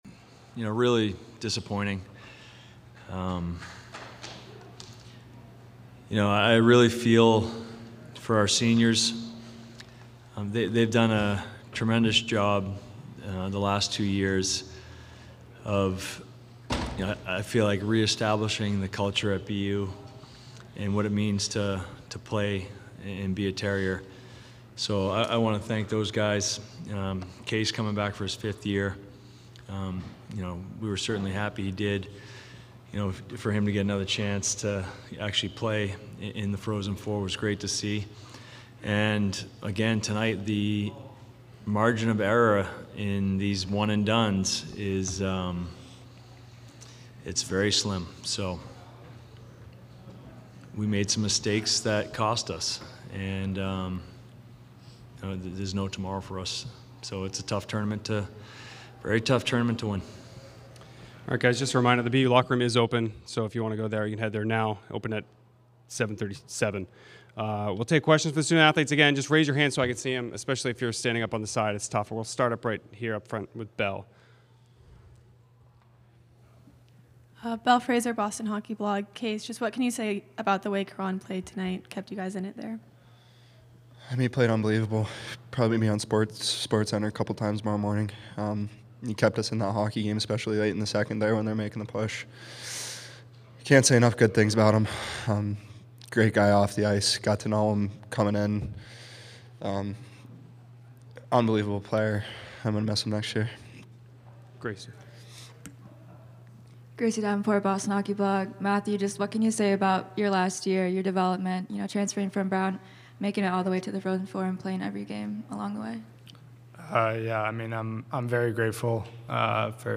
Men's Ice Hockey / Denver Postgame Press Conference (4-11-24)